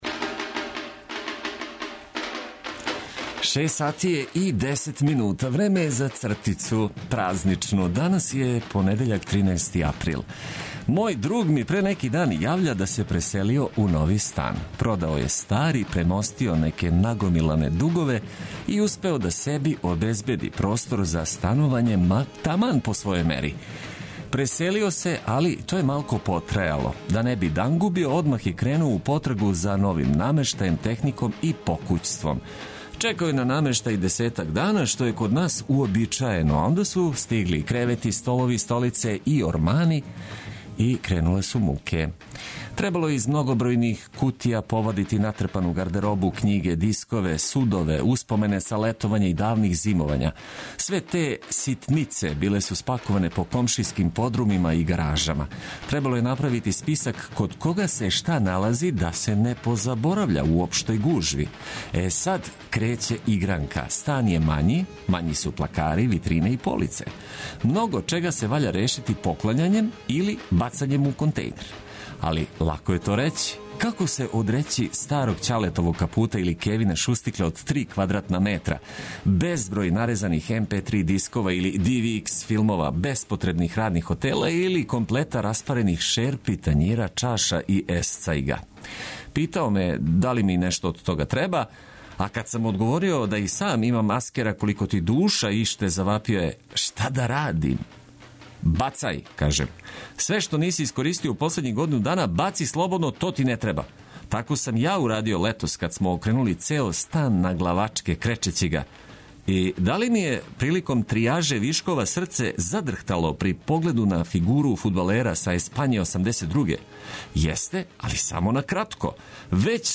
Чућемо како се Васкрс прослављао у Србији и окружењу, а уз много пажљиво одабране музике потрудићемо се да лепо започенете нов дан.